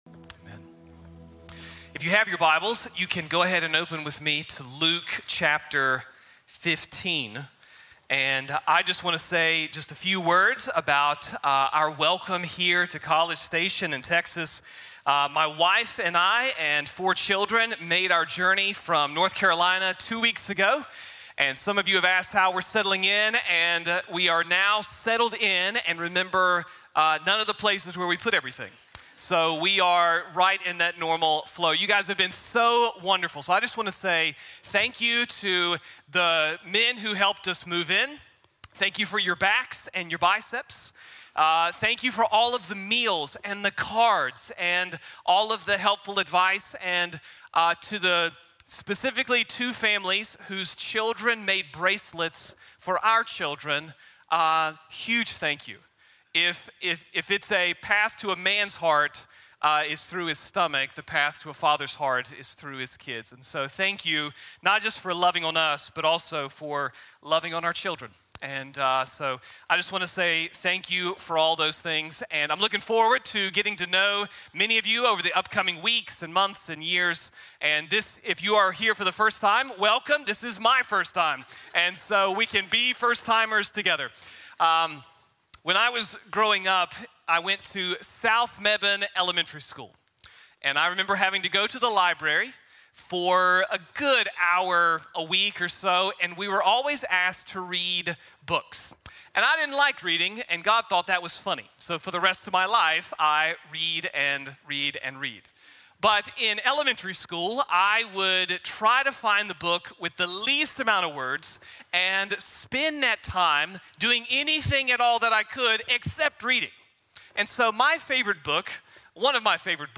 Lessons from the Storm | Sermon | Grace Bible Church